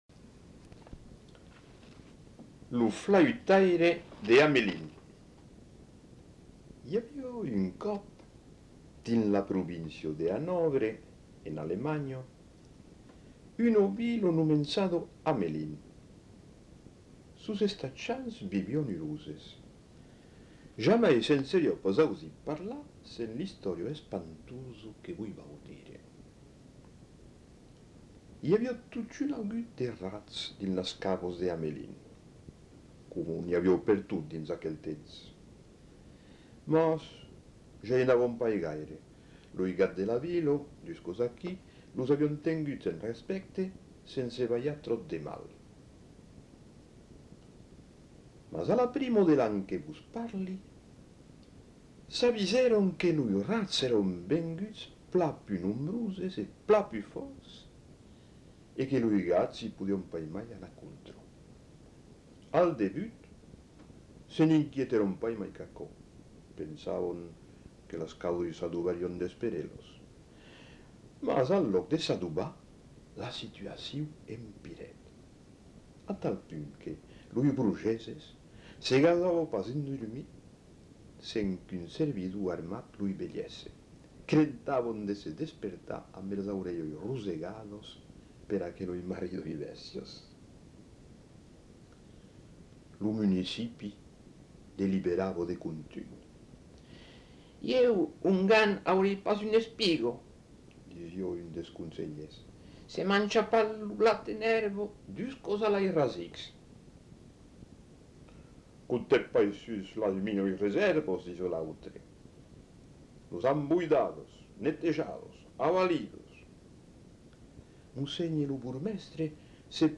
Genre : conte-légende-récit
Type de voix : voix d'homme Production du son : lu